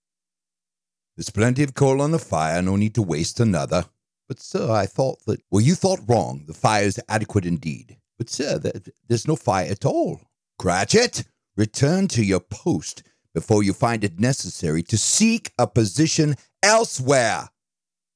Cartoon & Character Voice Overs | The Voice Realm